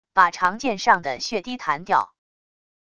把长剑上的血滴弹掉wav音频